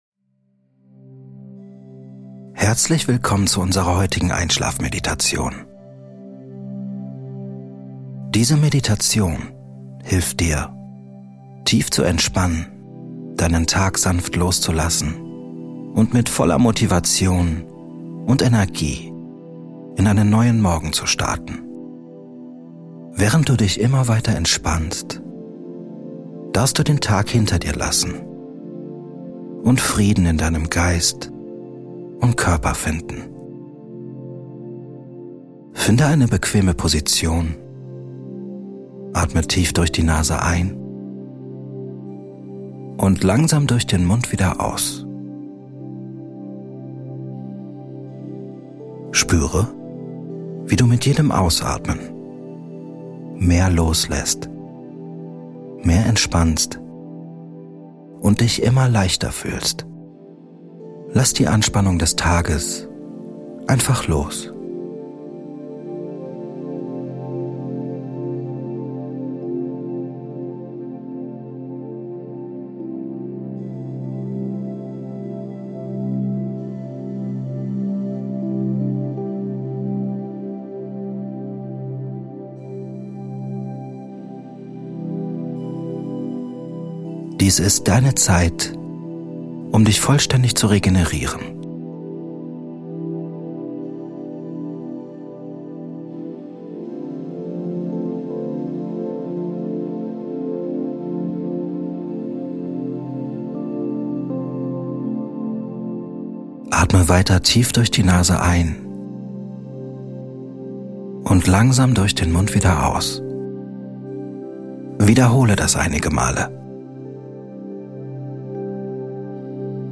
Sanft einschlafen & voller Energie aufwachen Diese geführte Einschlafmeditation hilft dir, den Tag loszulassen, deinen Geist zu beruhigen und Körper sowie Seele in einen Zustand tiefer Entspannung zu versetzen.